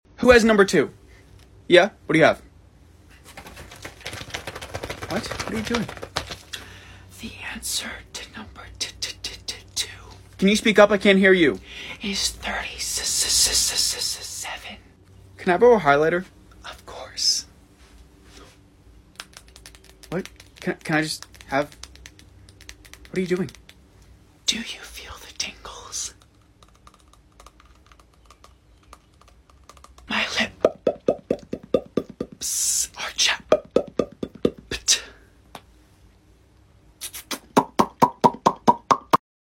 The kid who is obsessed woth ASMR